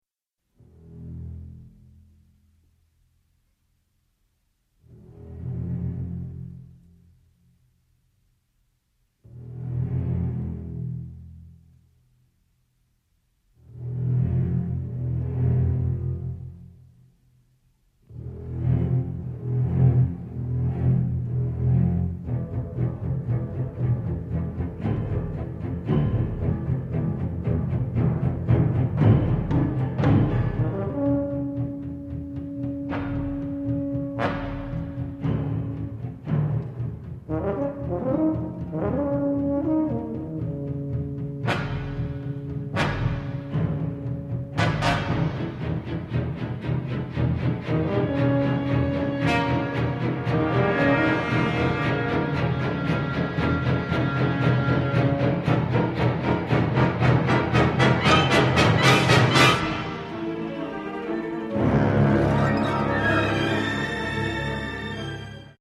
Musique au caractère
INQUIÉTANT